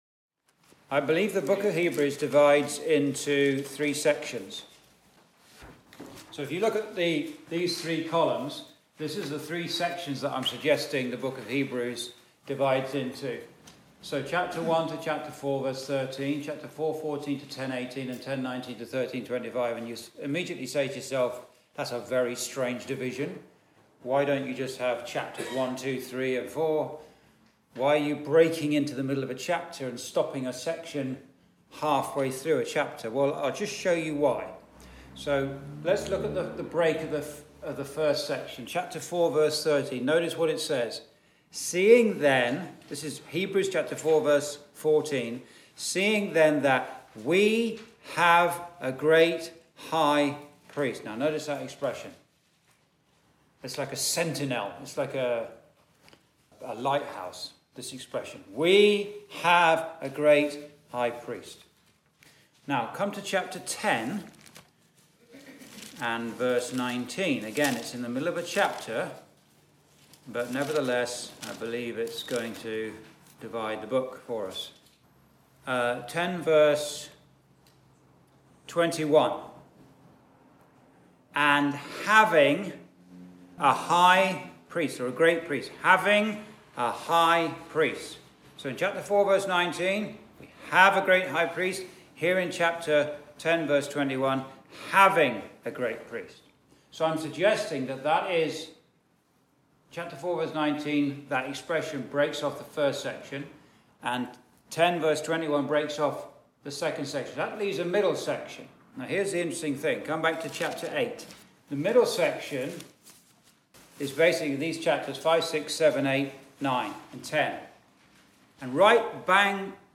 (Recorded in Chalfont St Peter Gospel Hall, 2024)
Verse by Verse Exposition